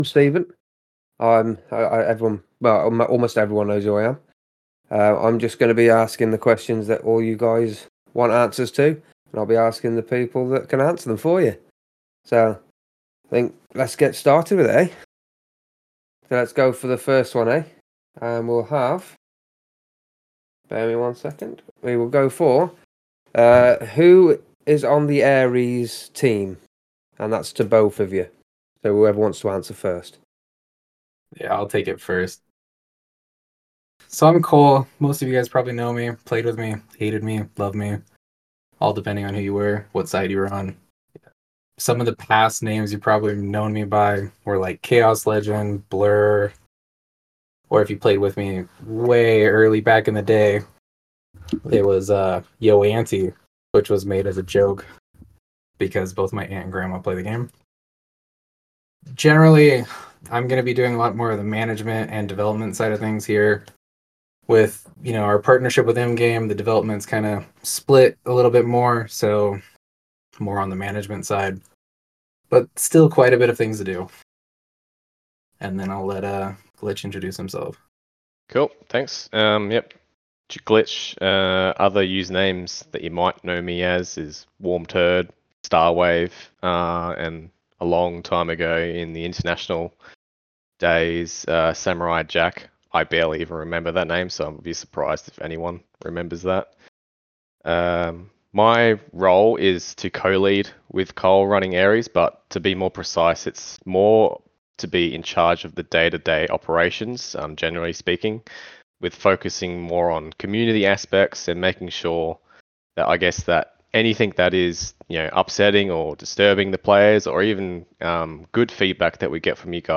Picture a cozy virtual gathering, where we discuss everything from recent updates to future plans. It's a chance for you to hear directly from the team and ask those burning questions you've been saving up.